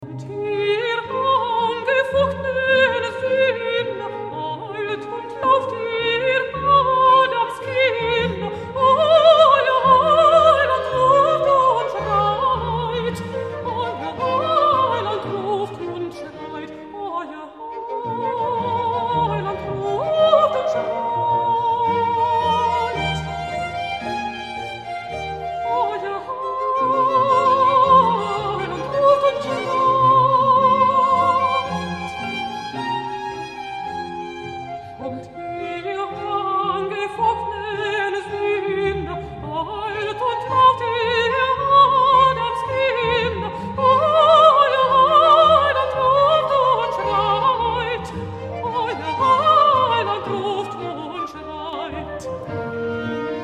BACH, cantate Bwv 30, aria alto - AERTSEN, la vendeuse de legumes-old.mp3